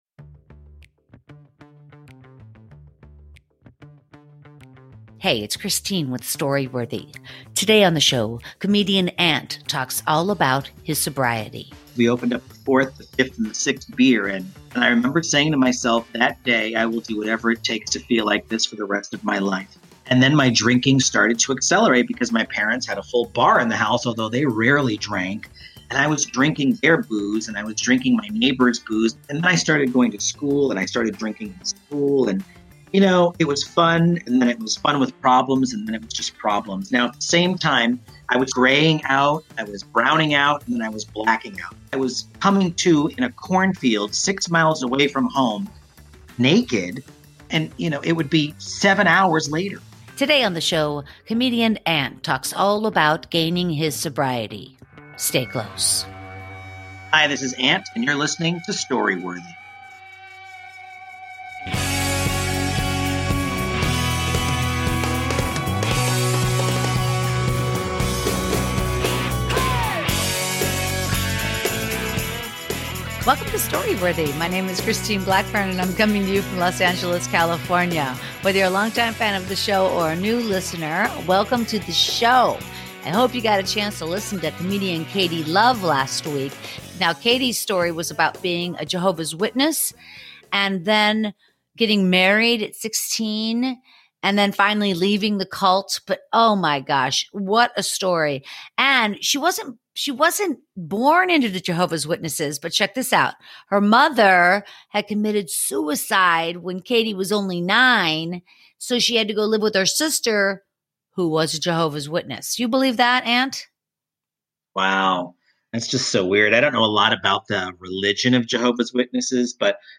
Sobriety with Comedian ANT
Today Ant shares a story about hitting rock bottom and coming out the other side and regaining his sobriety.